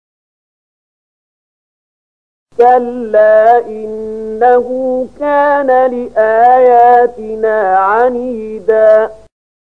074016 Surat Al-Muddatstsir ayat 16 bacaan murattal ayat oleh Syaikh Mahmud Khalilil Hushariy: